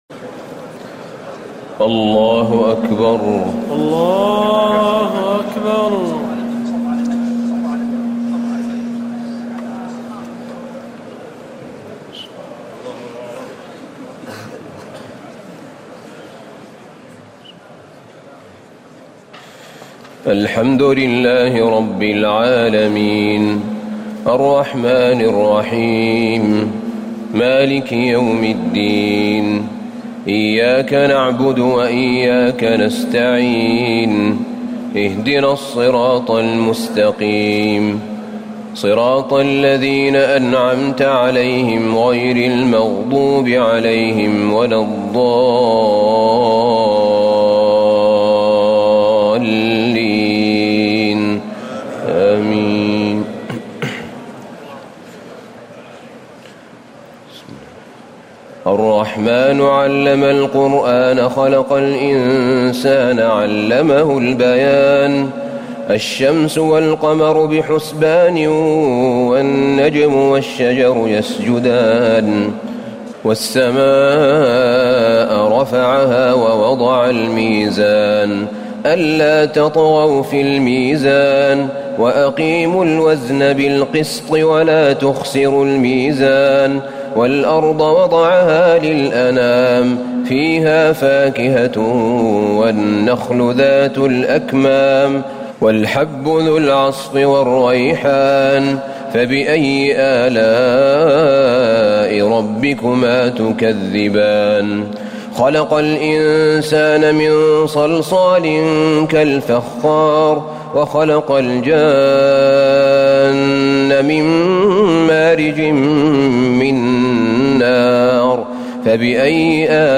تراويح ليلة 26 رمضان 1439هـ من سور الرحمن الواقعة و الحديد Taraweeh 26 st night Ramadan 1439H from Surah Ar-Rahmaan and Al-Waaqia and Al-Hadid > تراويح الحرم النبوي عام 1439 🕌 > التراويح - تلاوات الحرمين